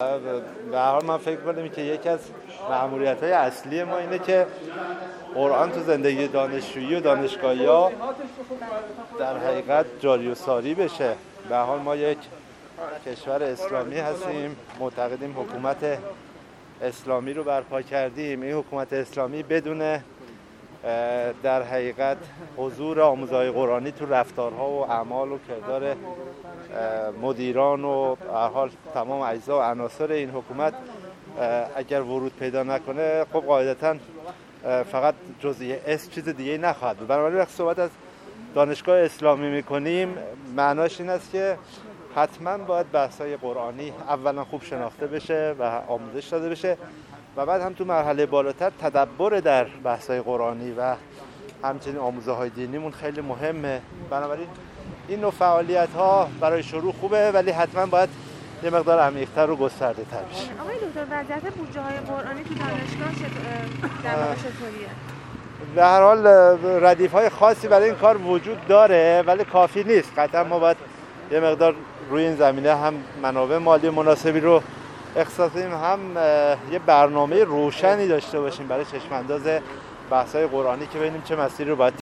سیدمحمد مقیمیسیدمحمد مقیمی، سرپرست دانشگاه تهران در گفت‌وگو با ایکنا، در مورد برنامه‌هایی که برای ارتقای فعالیت‌های قرآنی در این دانشگاه مورد توجه قرار گرفته است، گفت: یکی از مأموریت‌های اصلی ما این است که قرآن در زندگی دانشجویی و دانشگاهیان جاری و ساری باشد.